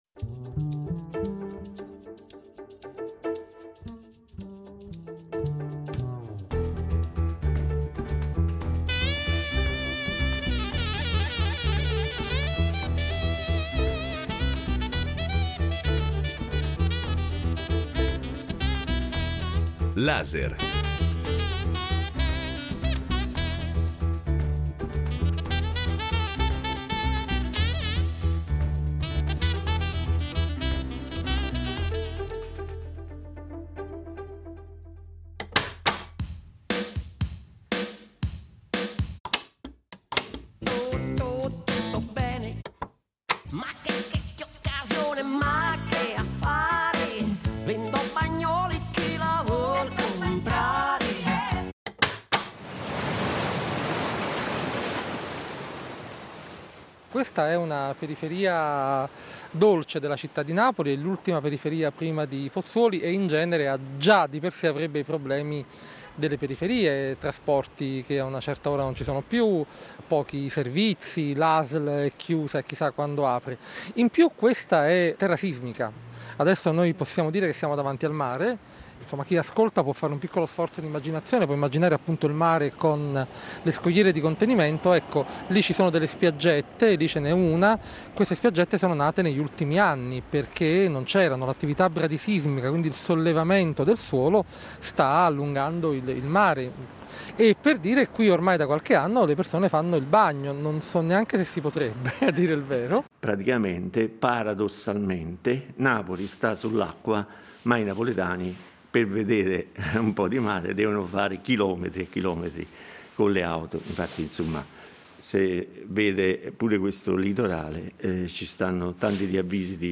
Reportage dal quartiere di Napoli che sarà sede operativa dell’America’s Cup, ma dove i cittadini protestano